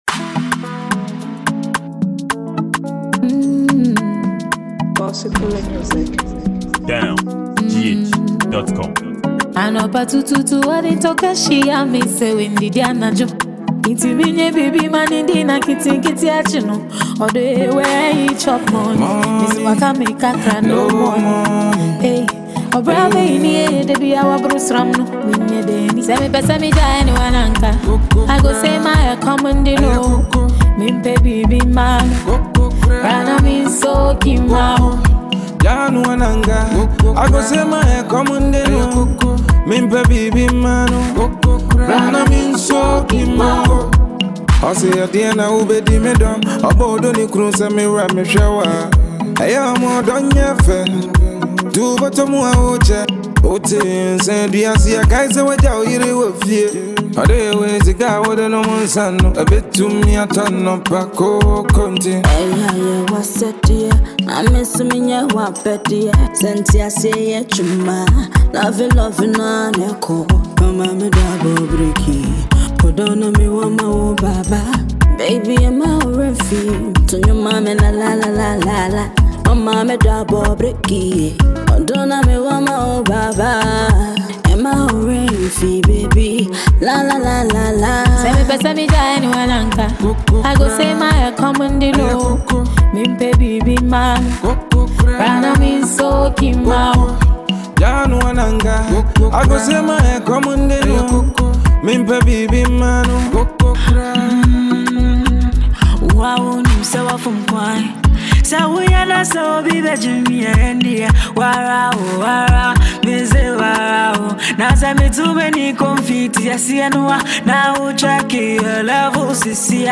Highlife Ghanaian singer and rcords producer